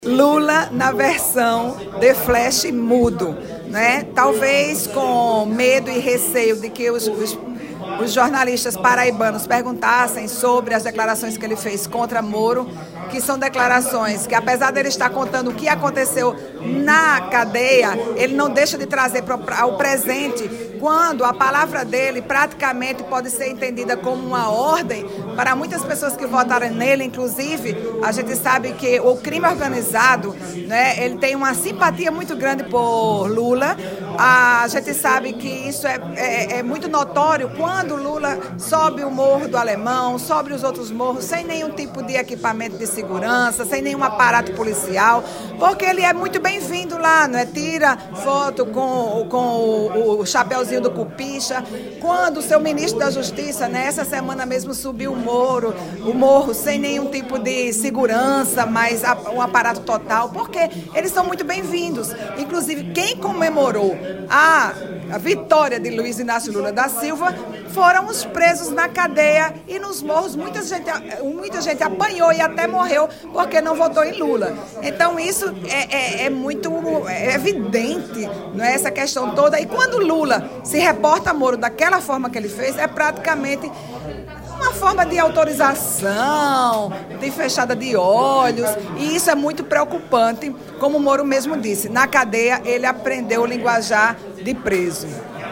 Os comentários da vereadora foram registrados pelo programa Correio Debate, da 98 FM, de João Pessoa, nesta quinta-feira (23/03).